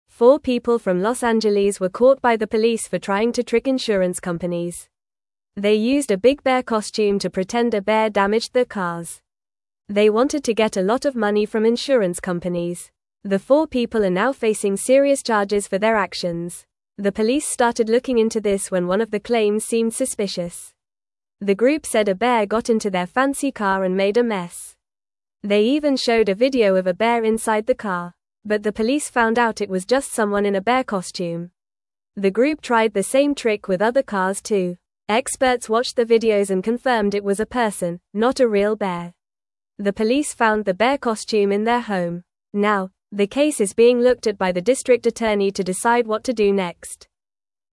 Fast
English-Newsroom-Lower-Intermediate-FAST-Reading-People-Pretend-Bear-Damaged-Cars-for-Money.mp3